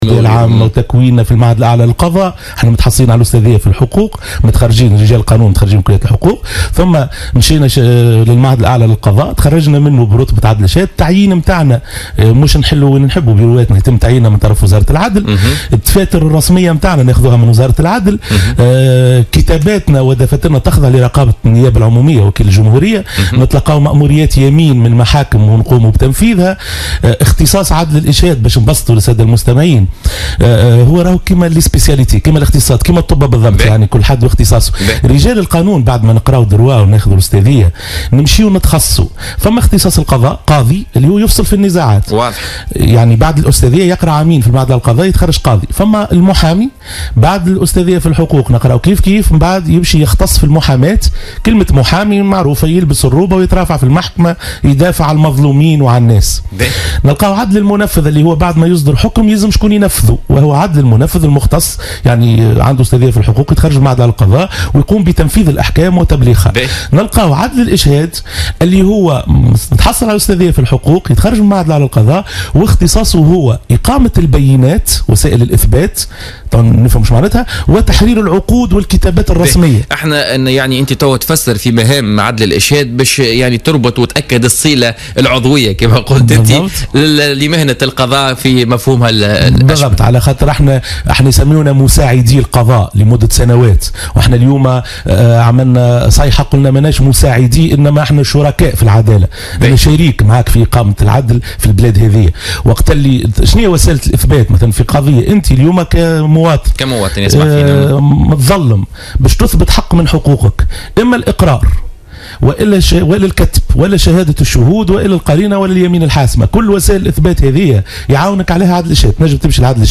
ضيف بوليتيكا